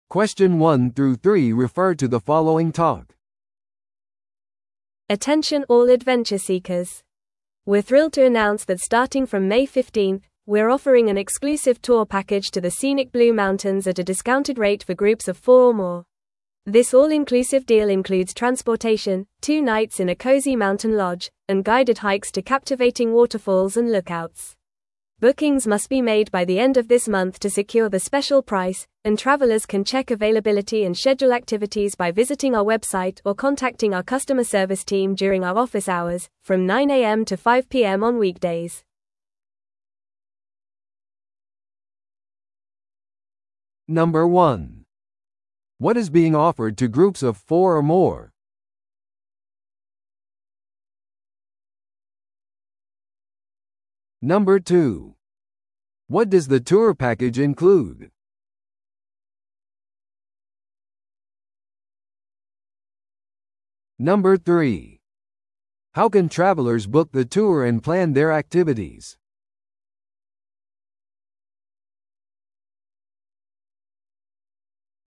TOEICⓇ対策 Part 4｜新規割引ツアーパッケージ – 音声付き No.083